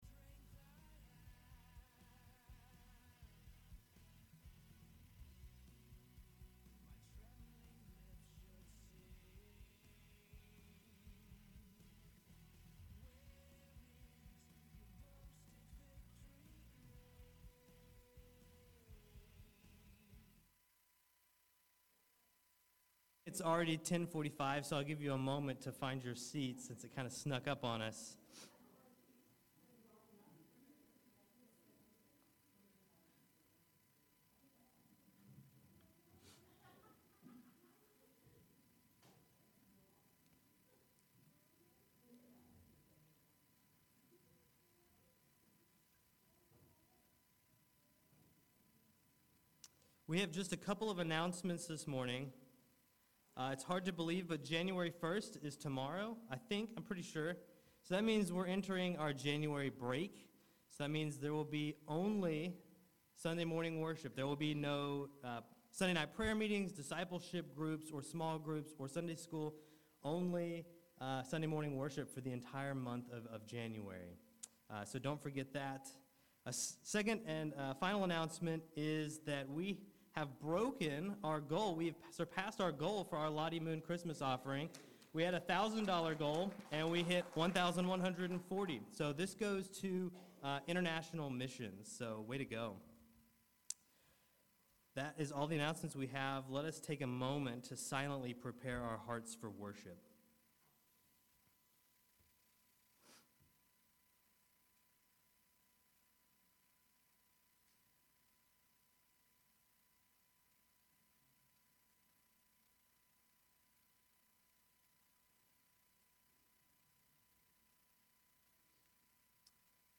December 31 Worship Audio – Full Service